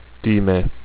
This is because of the dead space that is inherent at the start and end of file, due to the delay between recording beginning and the speech sample starting (and similarly at the end).